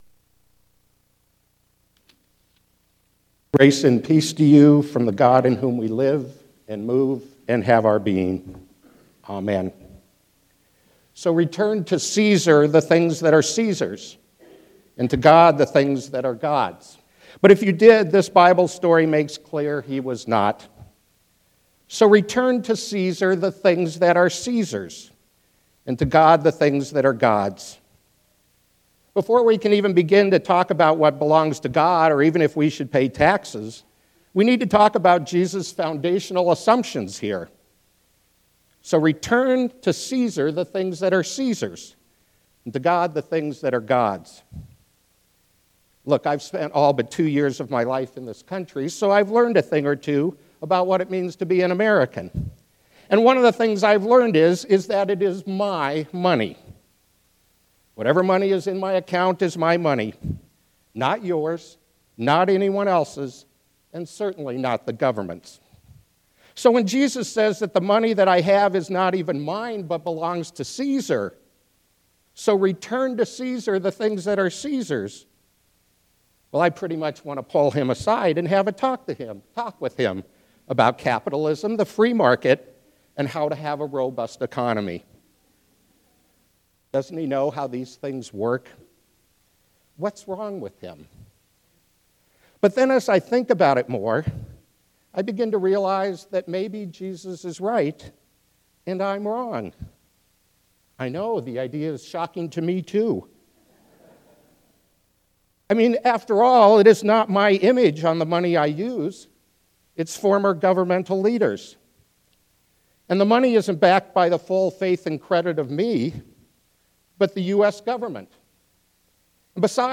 Sermon 12.3.2017